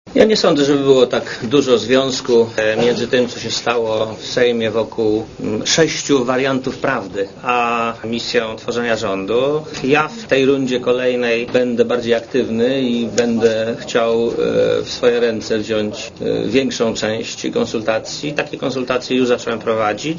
Posłuchaj Marka Belki
Podczas spotkania z przedstawicielami samorządów wiejskich powiatu łowickiego Marek Belka powiedział, że w poniedziałek przedstawi prezydentowi Aleksandrowi Kwaśniewskiemu dwie możliwe daty zaprzysiężenia rządu.